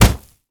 punch_grit_wet_impact_05.ogg